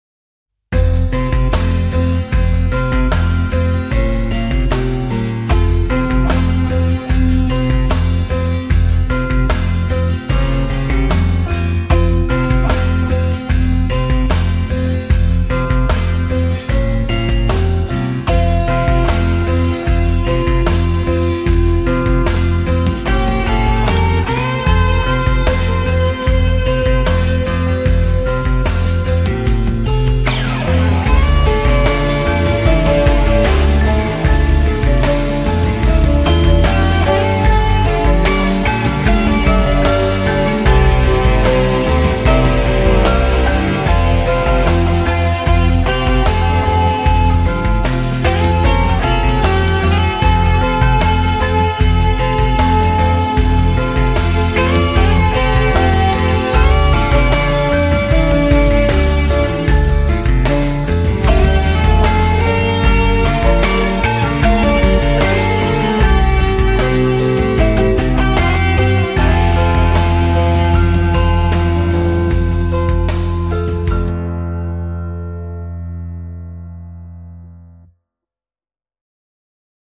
melodieux - rock - aerien - pop